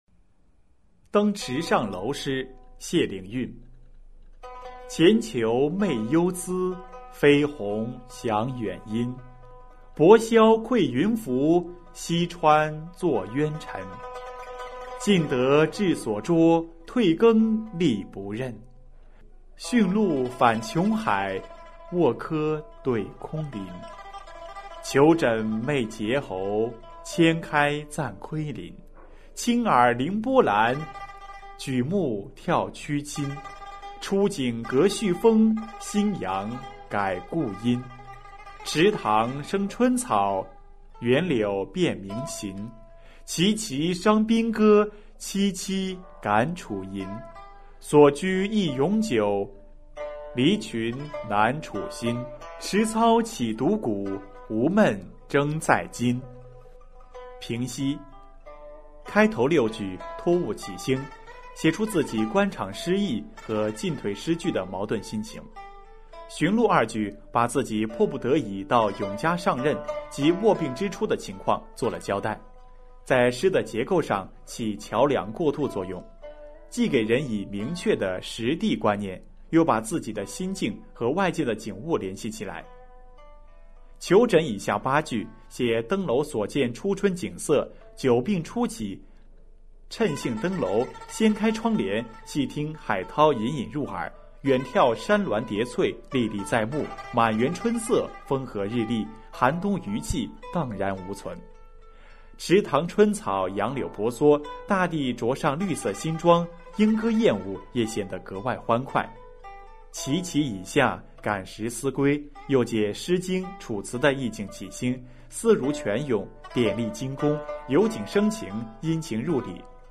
谢灵运《登池上楼》简析（含朗读）